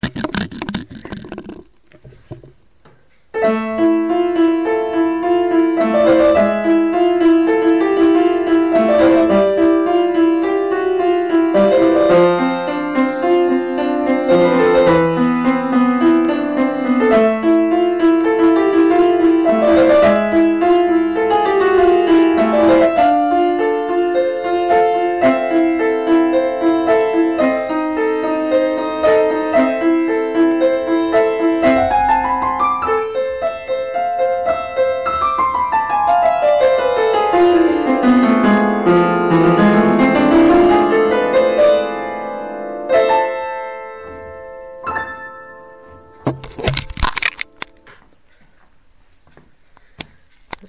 אהבתי את המשחקים על המינור מאז'ור....